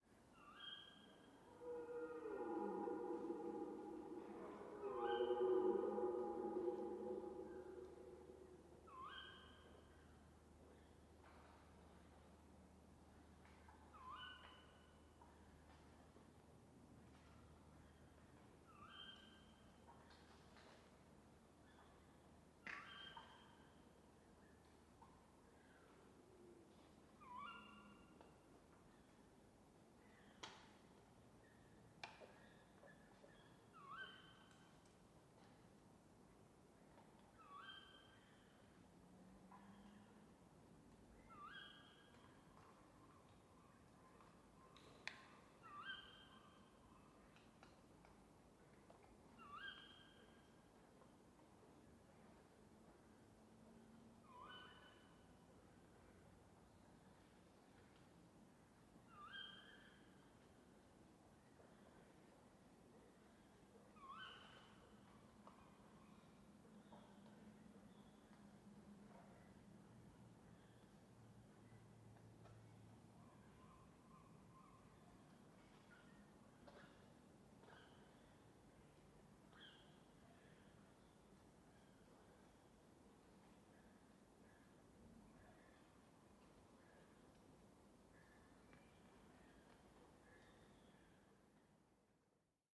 Звуки ночного леса
Жуткий ночной лес с пугающими криками животных и птиц